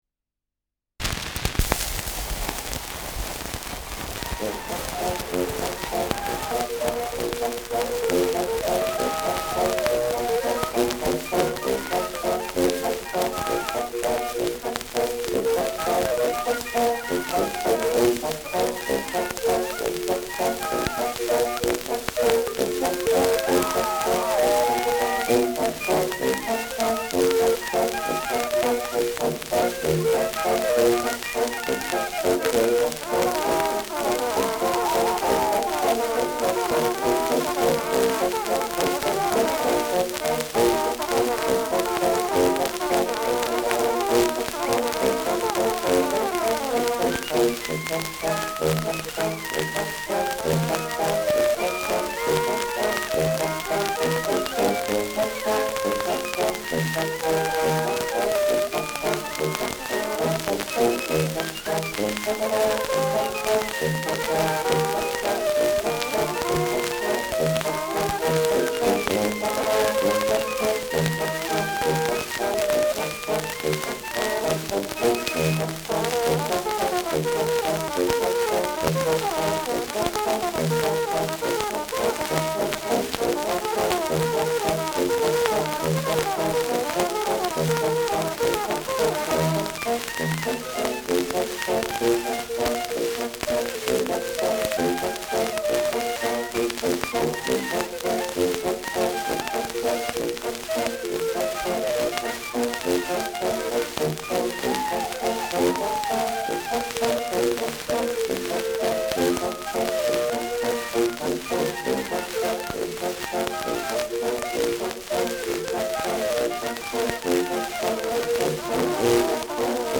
Schellackplatte
Starkes Grundrauschen : Durchgehend leichtes bis stärkeres Knacken
[Nürnberg] (Aufnahmeort)